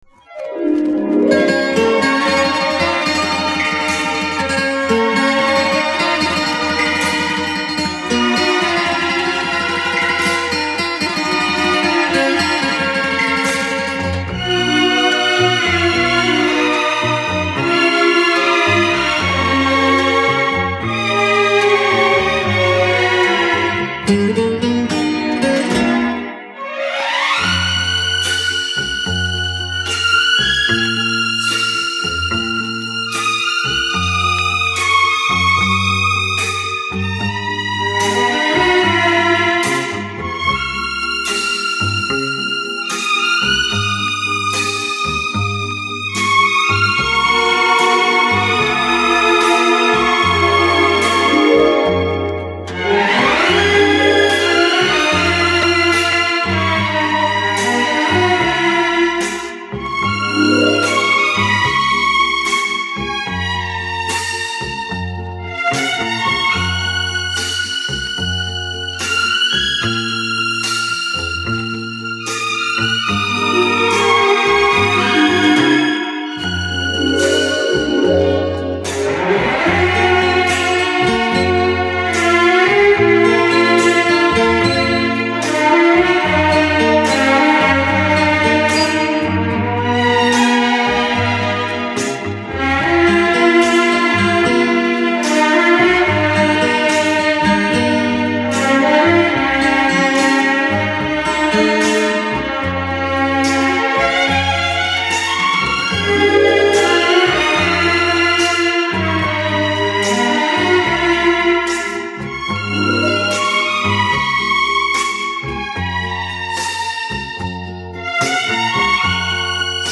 Французский флейтист, пианист и композитор.